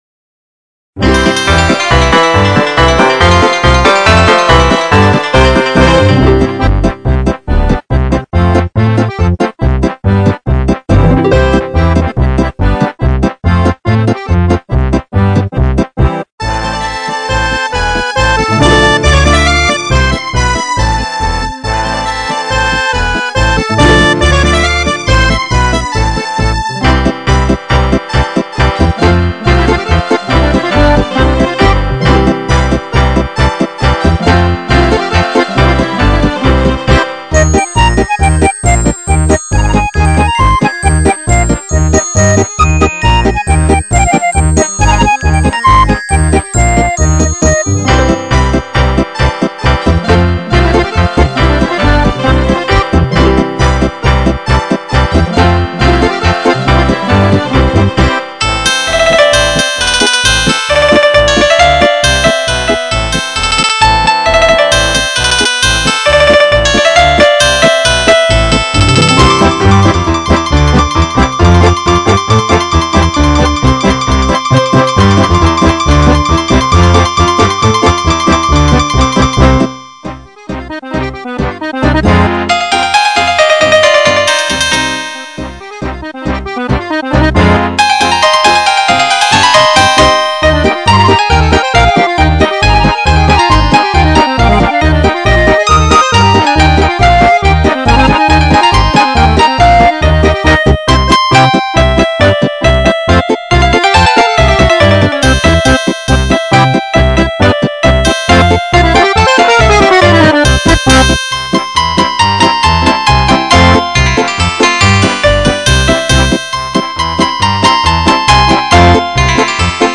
Есть простой минус